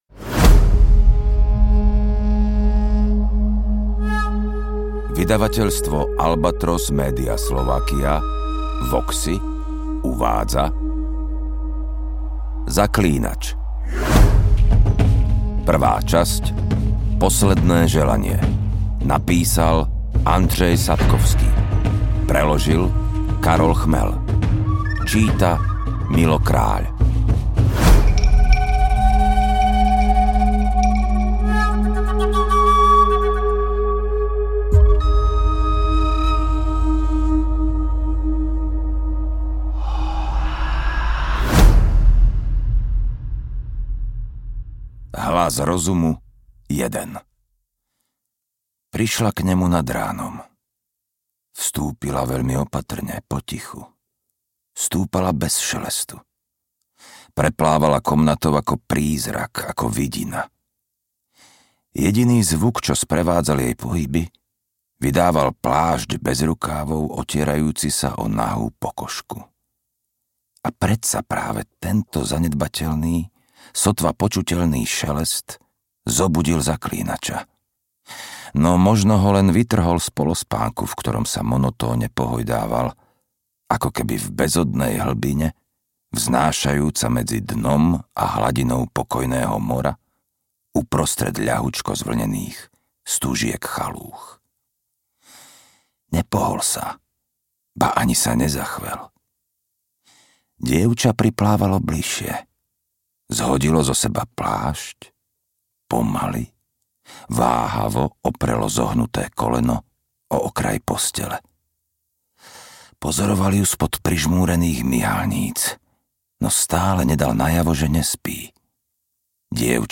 AudioKniha ke stažení, 58 x mp3, délka 13 hod. 16 min., velikost 766,2 MB, slovensky